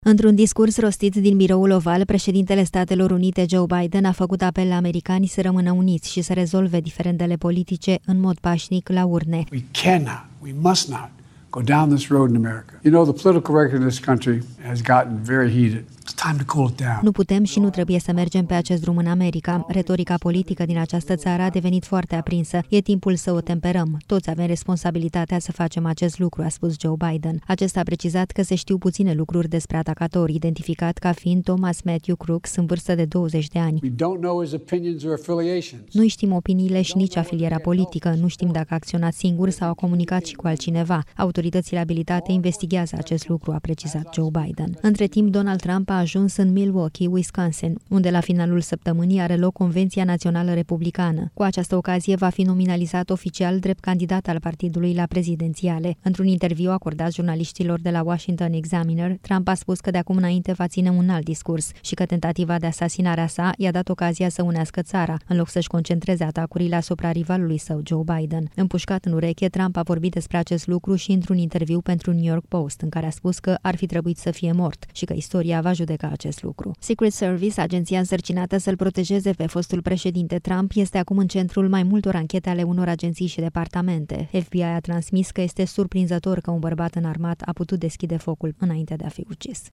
Într-un discurs rostit din Biroul Oval, președintele Statelor Unite, Joe Biden a făcut apel la americani să rămână uniți și să rezolve diferendele politice, în mod pașnic, la urne.